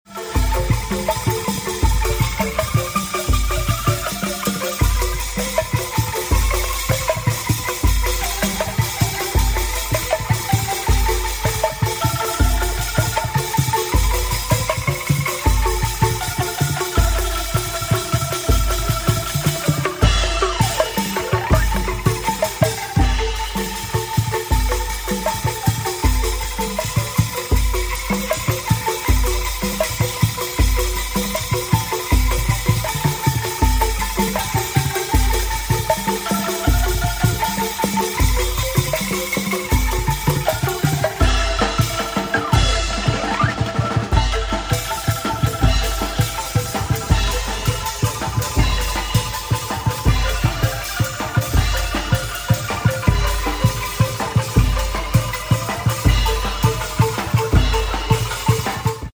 An interesting Javanese instrument called sound effects free download
An interesting Javanese instrument called the Angklung, made from a series of tuned bamboo pipes suspended in such a way that they each create a repeated note after being struck with a stone-shaped hard rubber beater. It's a mechanically-generated delay effect!